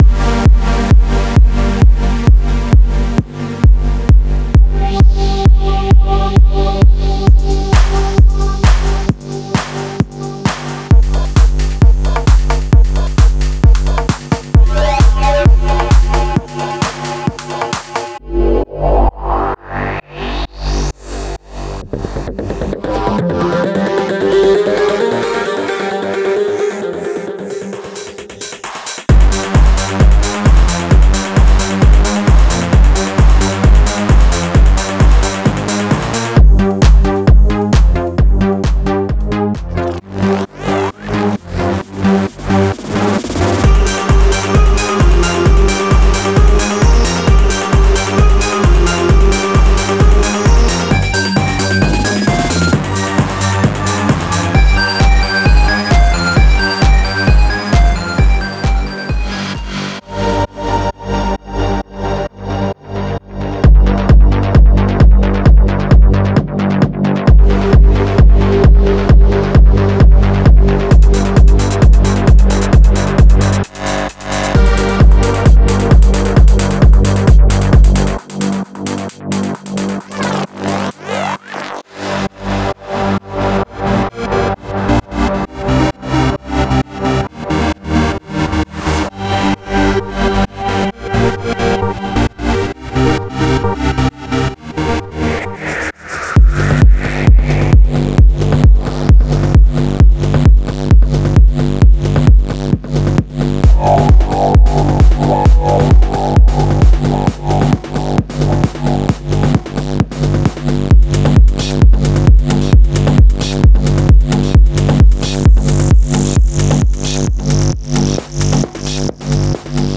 Progressive Techno Loops - Melodic Techno Pack